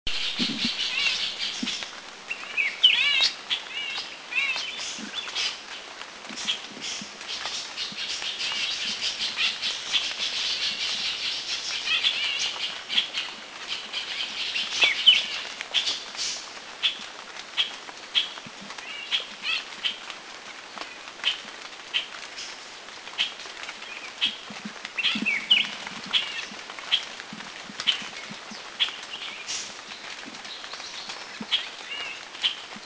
Grey-eyed Bulbul 2
Grey-eyedBulbul2.mp3